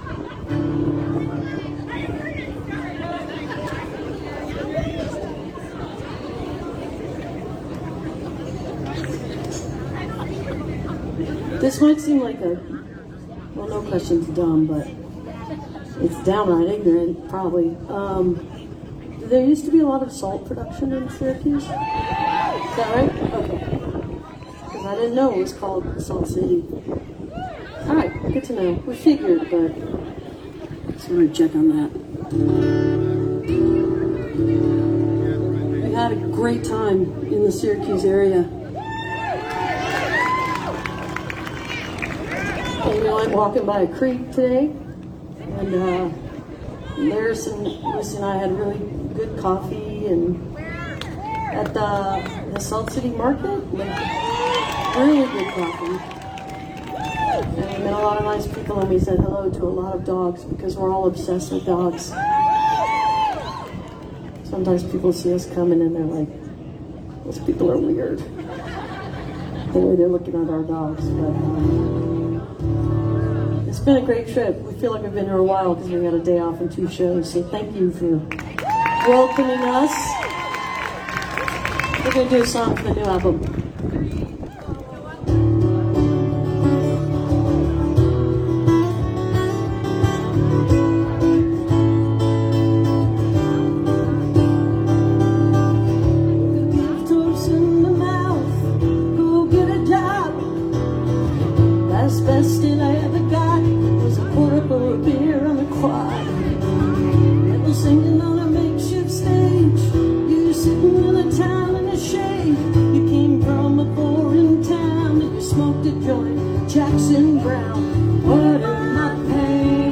(captured from a facebook livestream)